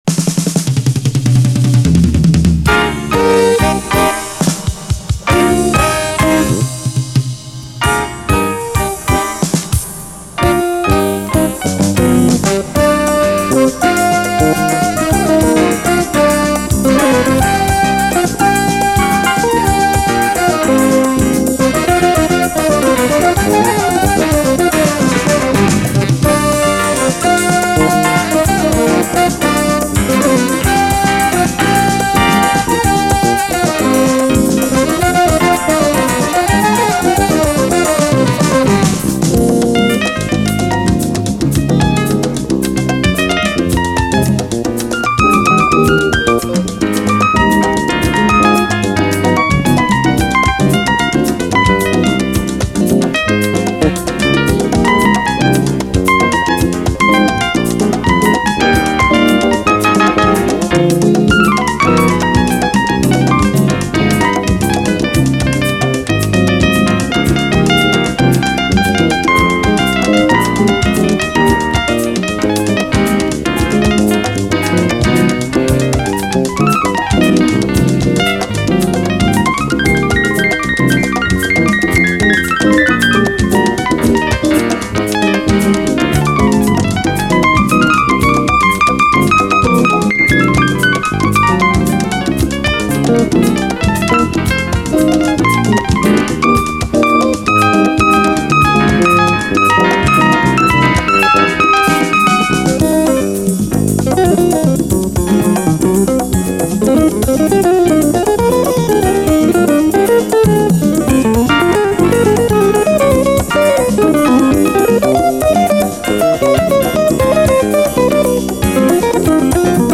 JAZZ, LATIN
80'Sキューバン・フュージョン〜ラテン・ジャズ盤！哀愁メロディーと意外性あるエレクトリック・アレンジ！
哀愁の美麗メロディーと80’Sキューバ特有の意外性あるエレクトリックなラテン・ジャズ・サウンド。